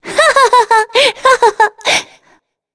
Shea-Vox_Happy3.wav